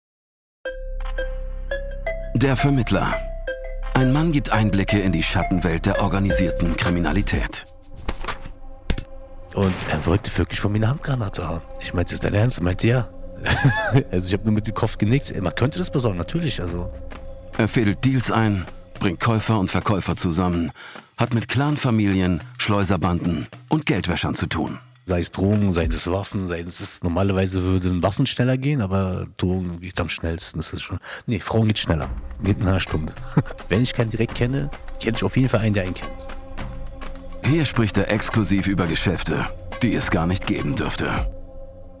Der Mann, der hier erzählt, kennt diese Strukturen.